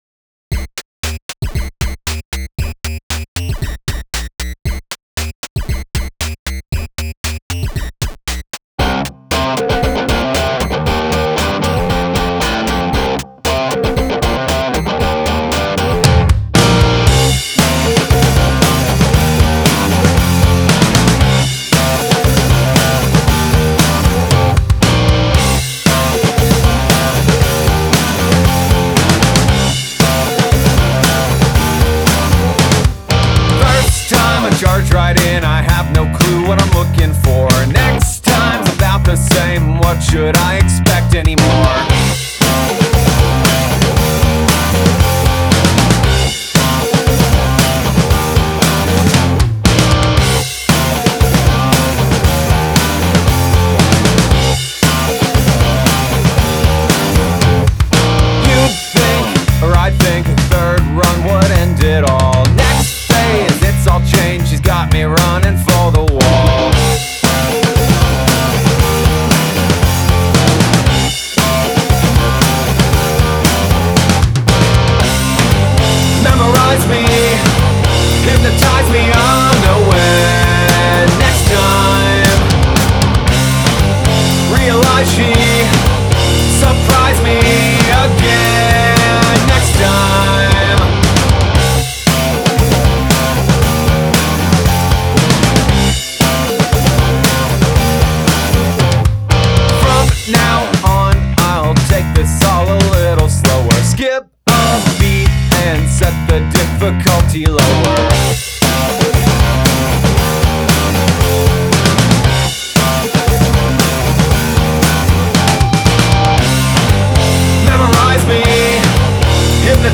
goofy riffy alt rock